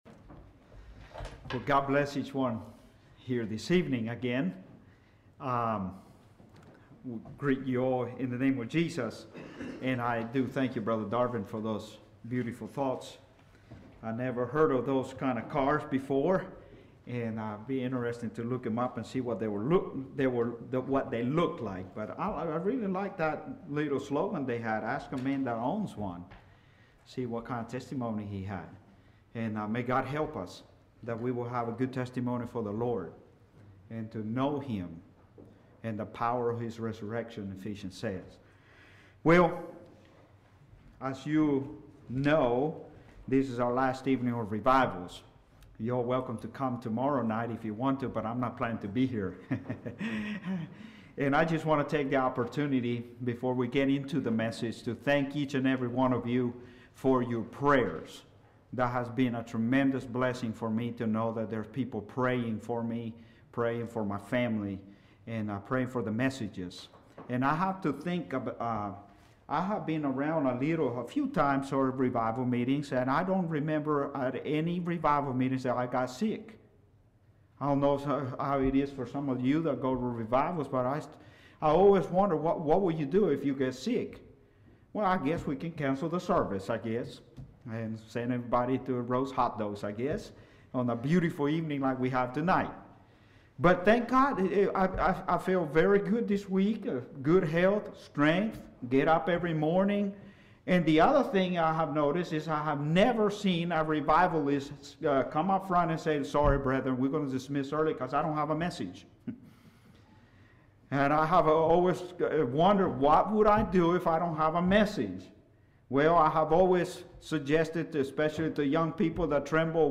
The devil wants to come to us as an angel of light. Categories: Evangelistic , Sin and Forces of Evil